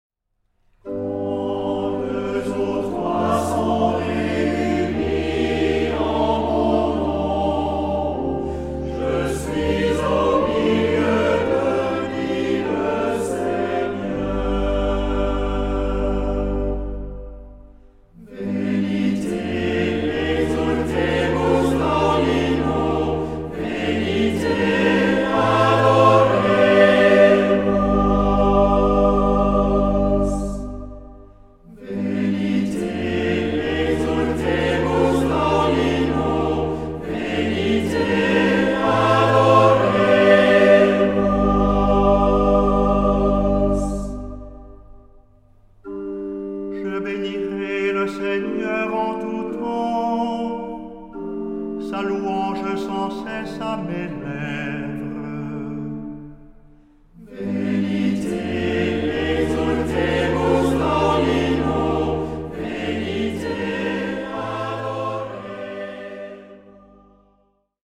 Género/Estilo/Forma: Sagrado ; tropario ; Salmo
Carácter de la pieza : con recogimiento
Tipo de formación coral: SATB  (4 voces Coro mixto )
Instrumentos: Organo (1)
Tonalidad : sol menor